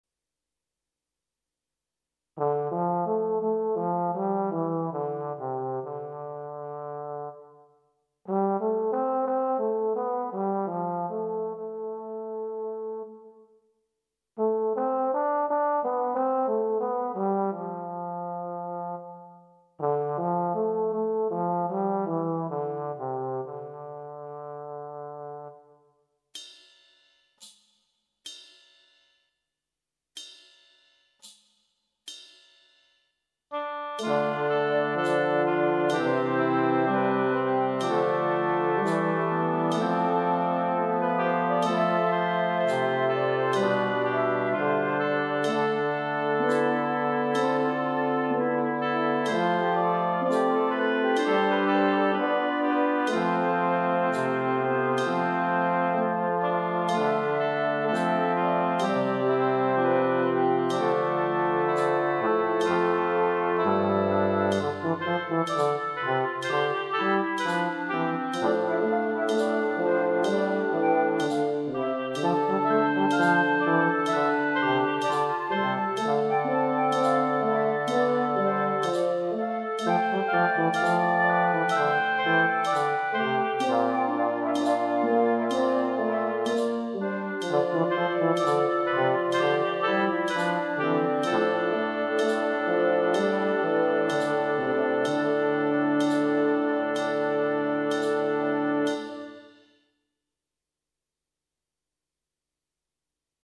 minus Drums